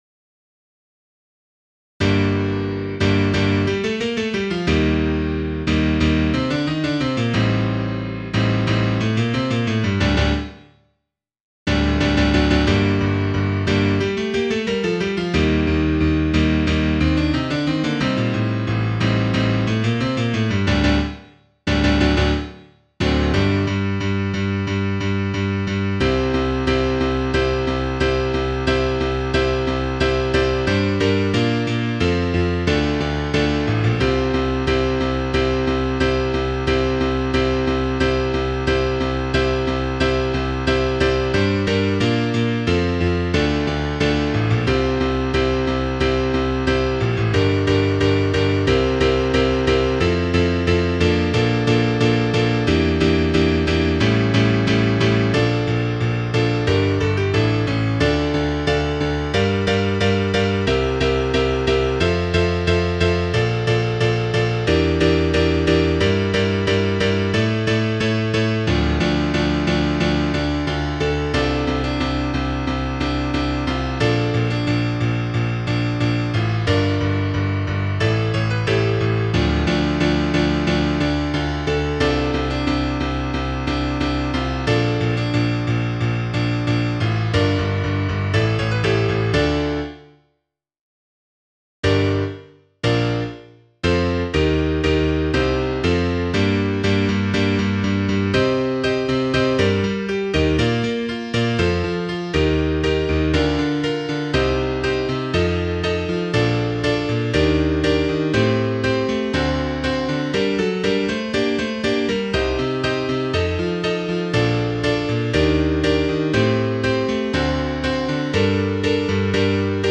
Piano
7_Fecit_potentiam_Piano.mp3